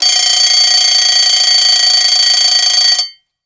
bomb_alarm_01.ogg